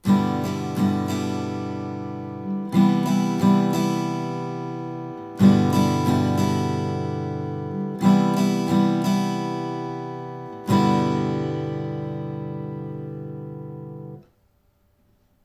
Rütmiharjutused (strum patterns)
2. Tüüpjärgnevus I-IV-I mažooris: